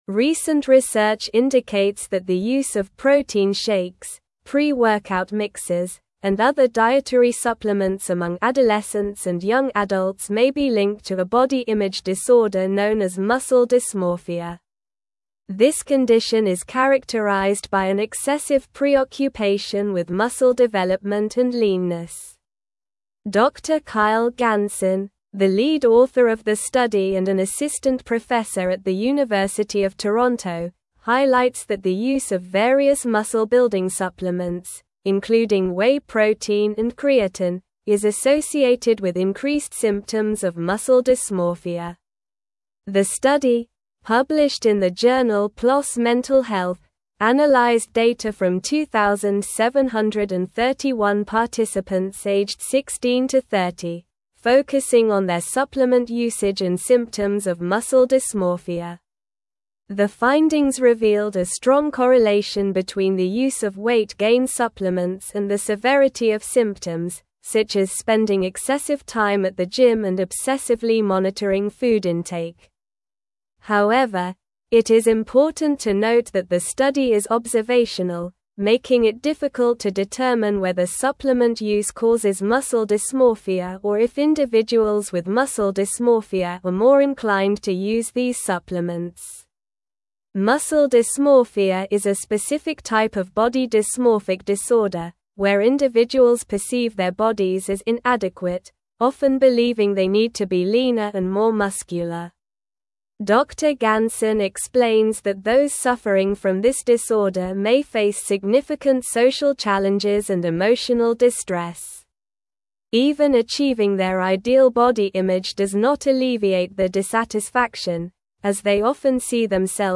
Slow
English-Newsroom-Advanced-SLOW-Reading-Link-Between-Supplements-and-Muscle-Dysmorphia-in-Youth.mp3